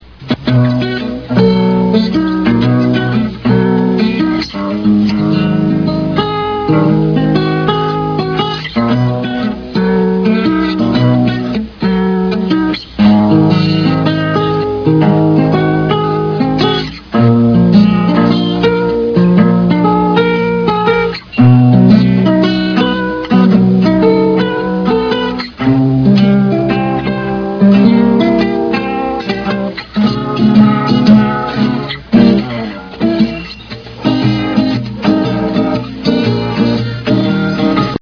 folk jam on an acoustic guitar